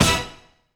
16BRASS01 -R.wav